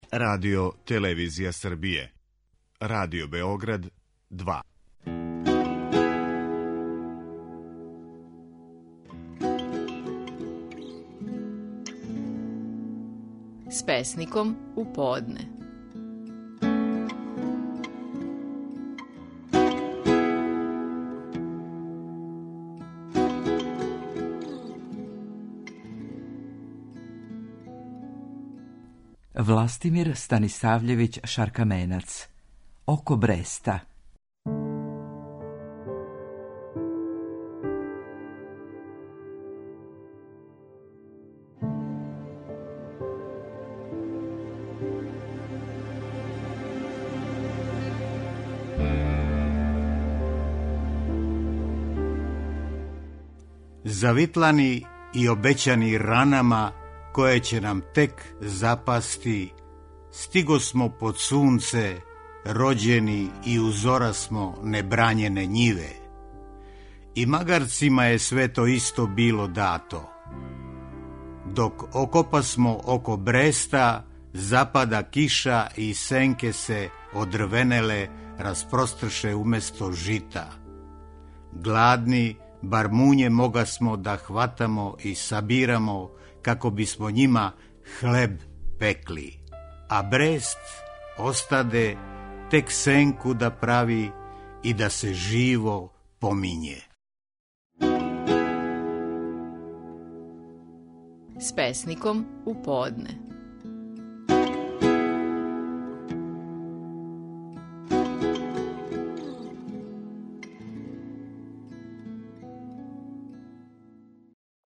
Стихови наших најпознатијих песника, у интерпретацији аутора
Властимир Станисављевић Шаркаменац говори своју песму „Око бреста".